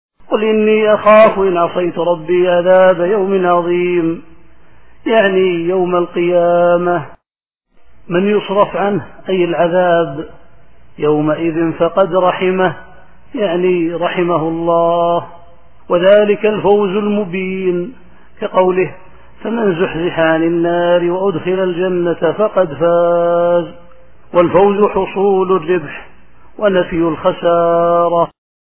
التفسير الصوتي [الأنعام / 16]